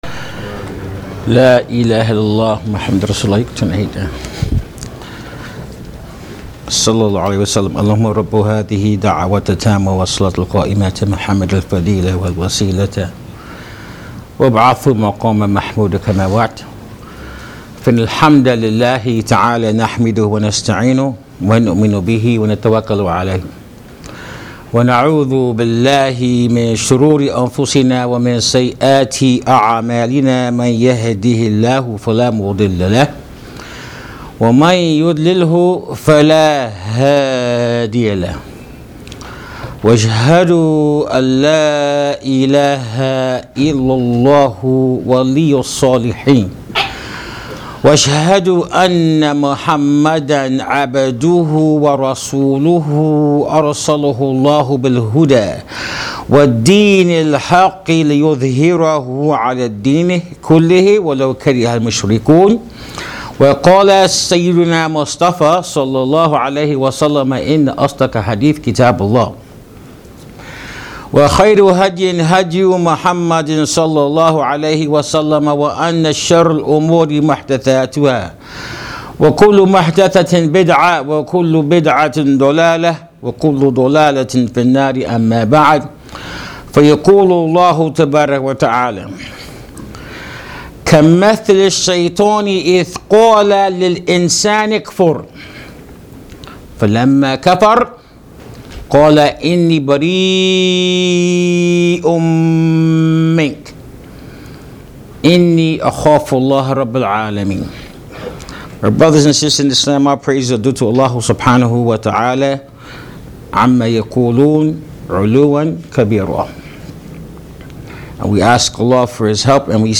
How does a person deal with temptation? During this free audio khutbatul Jum’ah at Masjid Ibrahim Islamic Center in Sacramento, we discuss temptation and the ways to deal with it according to the Quran and the Sunna.